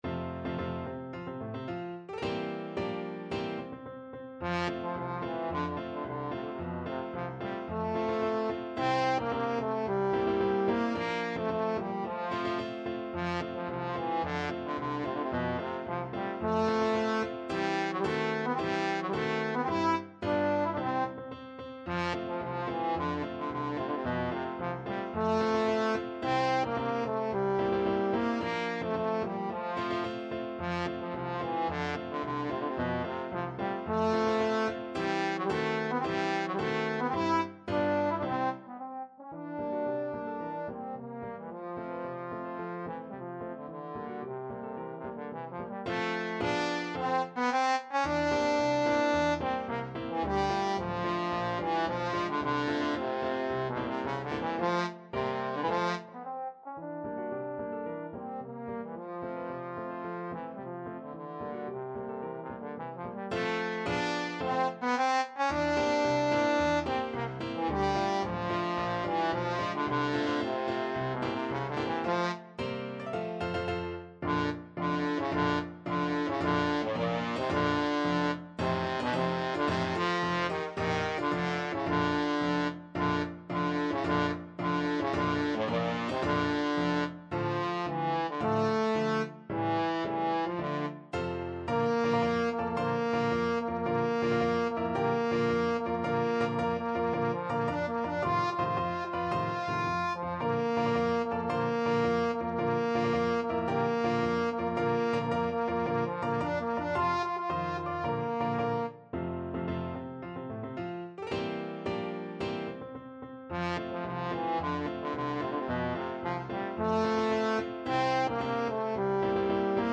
Classical Komzak, Karel Erzherzog Albrecht March Trombone version
Trombone
2/2 (View more 2/2 Music)
F major (Sounding Pitch) (View more F major Music for Trombone )
March =c.110